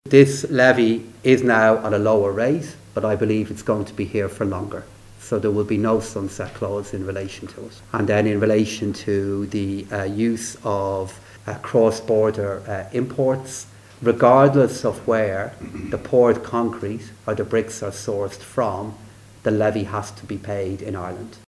This afternoon, Minister Pascal Donohoe told reporters there will be changes, but the levy will go ahead, and probably for longer than first envisaged………